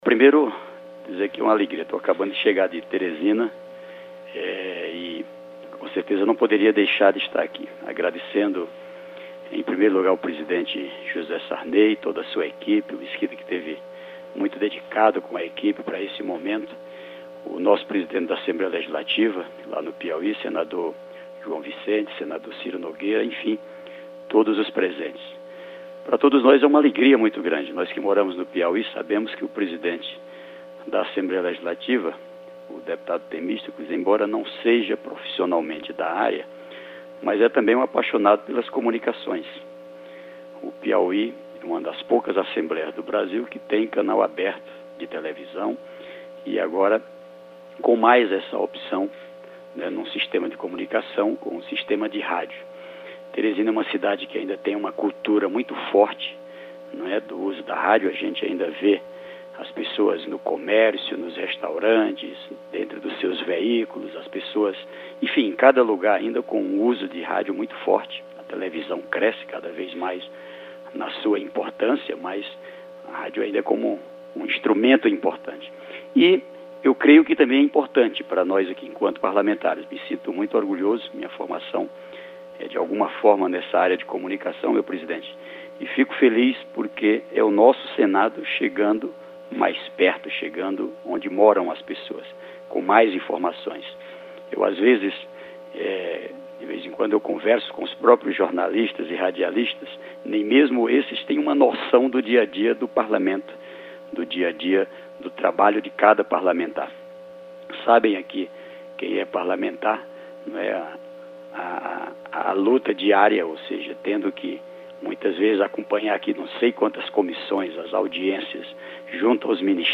Pronunciamento do senador Wellington Dias